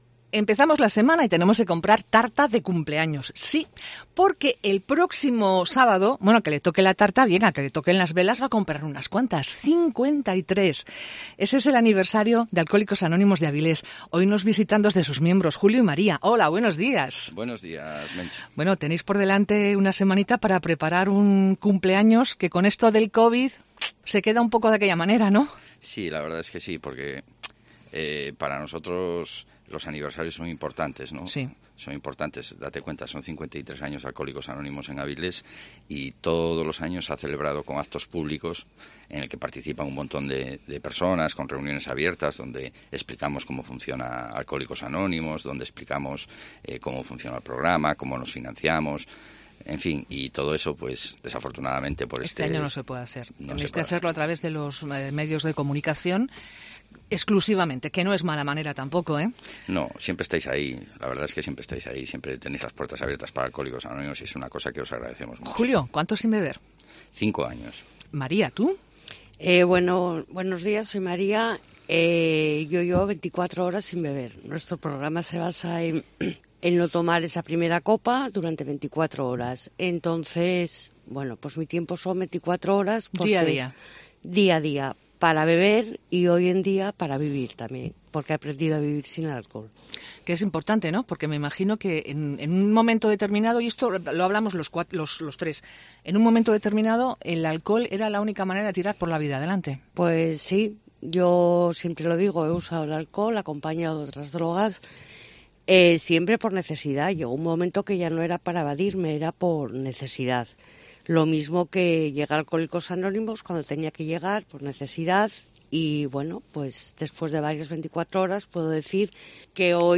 Entrevista con miembros de Alcohólicos Anónimos